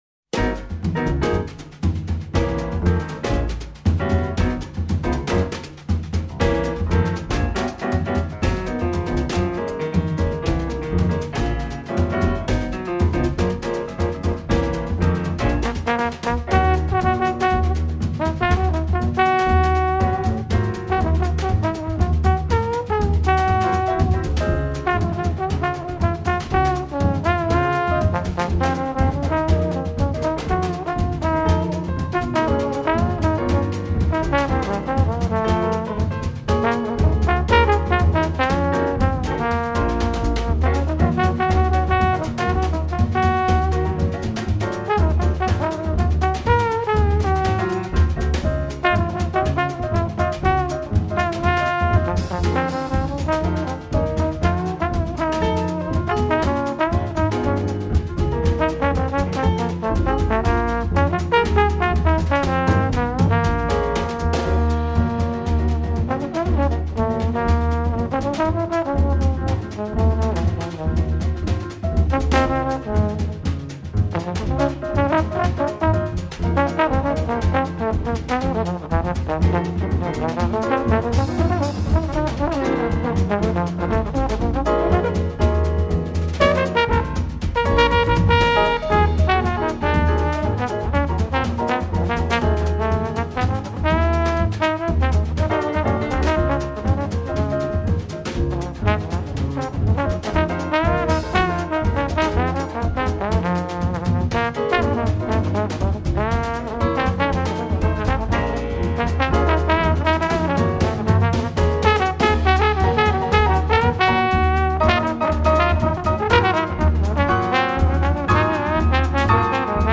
en concert
Grand Théâtre Angers / 20h30
La version manouche
Puis une adaptation façon Nouvelle-Orléans très réussie
guitares
contrebasse
saxophone alto, vocal
trombone
piano
batterie